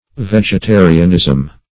Vegetarianism \Veg`e*ta"ri*an*ism\, n.